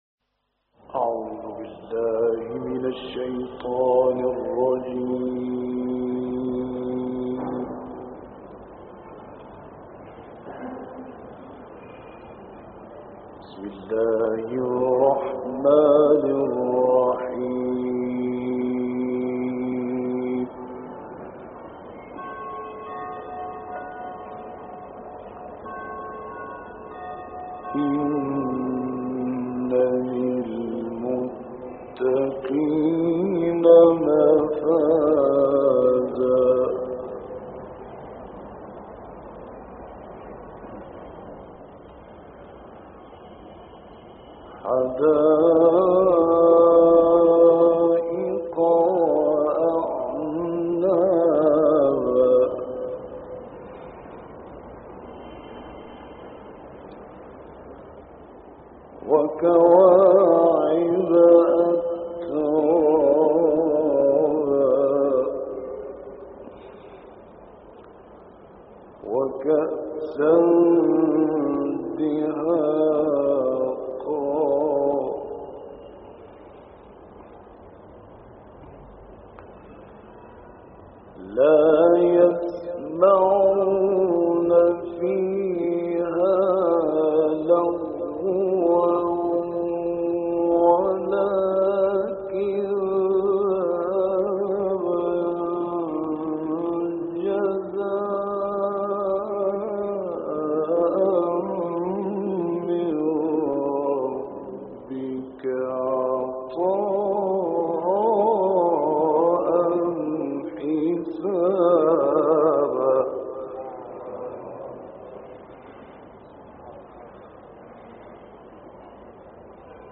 دانلود قرائت سوره های نباء ، فجر ، بلد و قریش - استاد راغب مصطفی غلوش